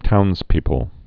(tounzpēpəl)